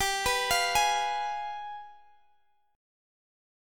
Listen to G7b9 strummed